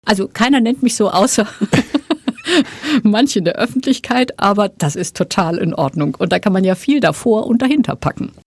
Bundesverteidigungsministerin Ursula von der Leyen war zu Gast in unserer Einsatzredaktion in Afghanistan und gewährte dabei tiefe Einblicke in ihr Privatleben.